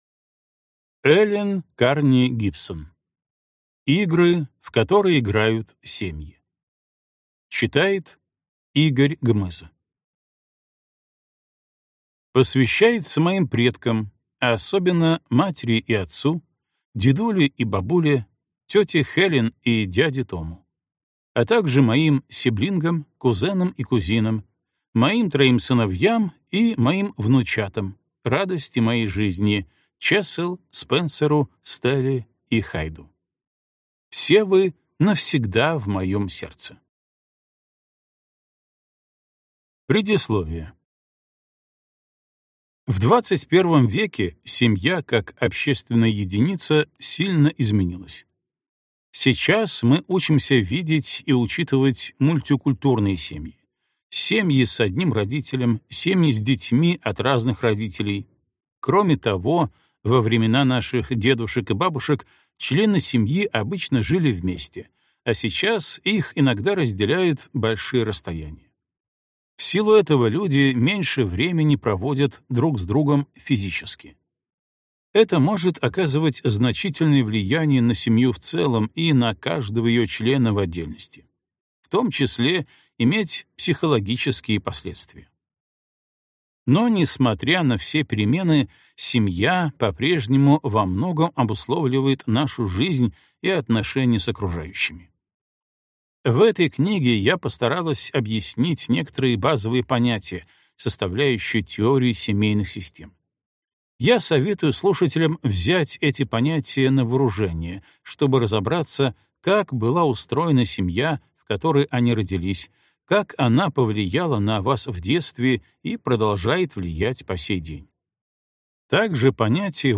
Аудиокнига Игры, в которые играют семьи | Библиотека аудиокниг